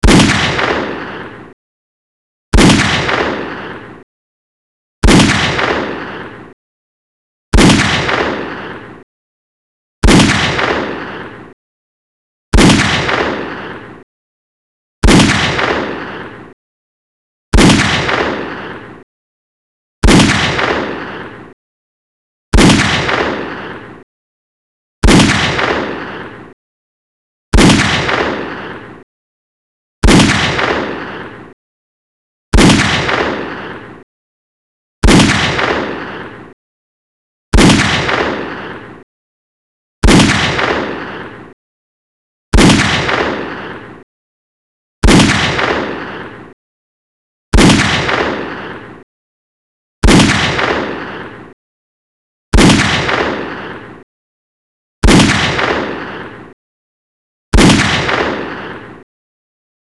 gunshotsWav.wav